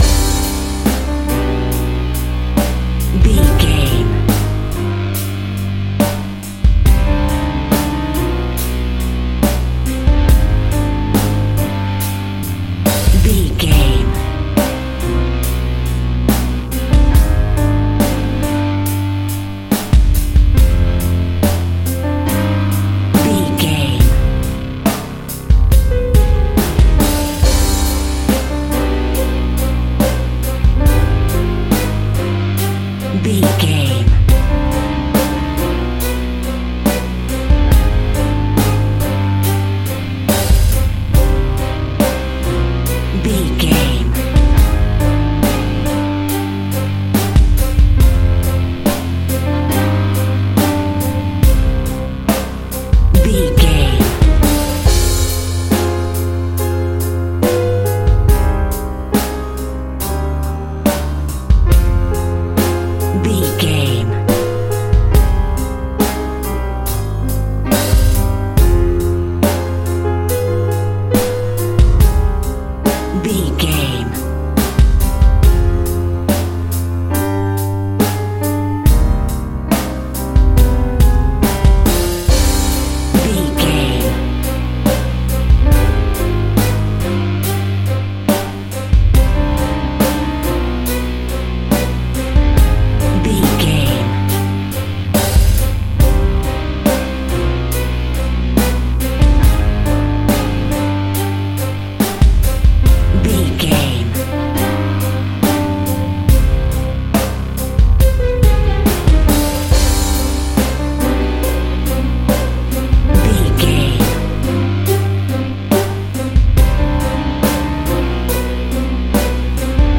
Aeolian/Minor
ominous
dark
suspense
haunting
eerie
electric guitar
bass guitar
drums
piano
strings
synth
pads